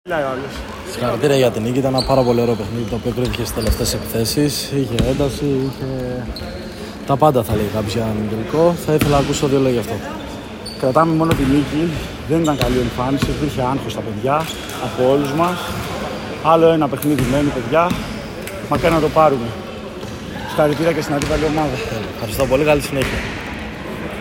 GAME INTERVIEWS: